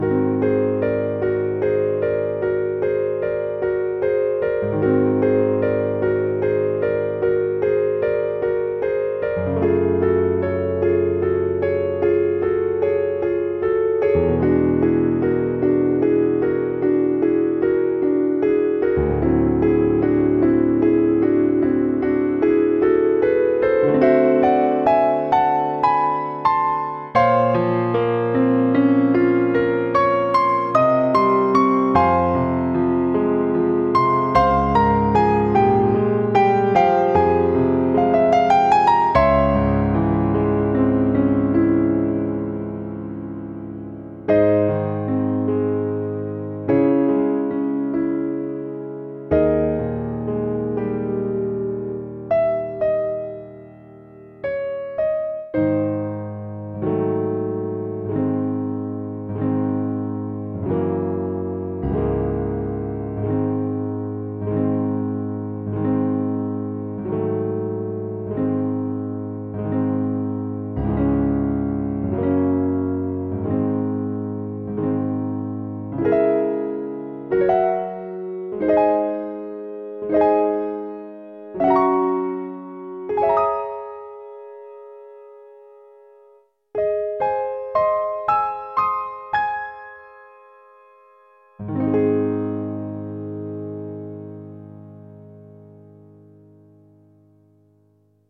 Hear the passage with just the accompaniment playing (this is what will be played at your audition)